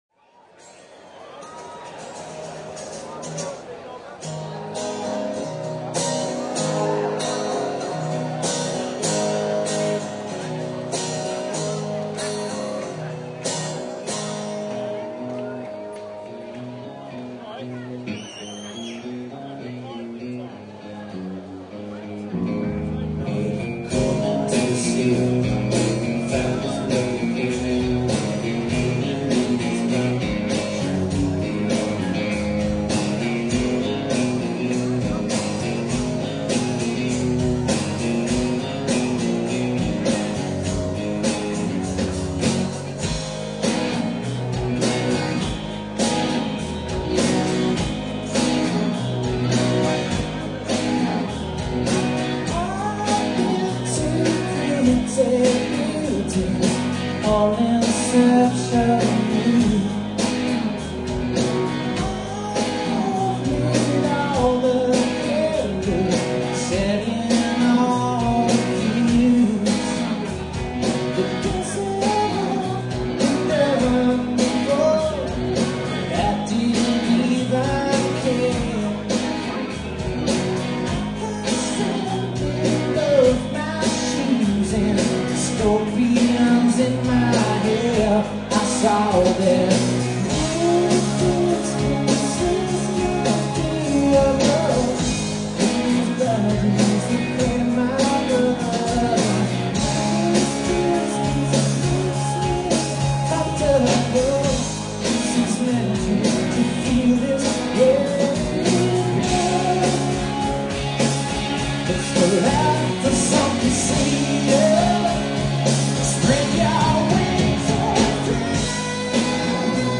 From The Electric Ballroom London, England (6-30-99)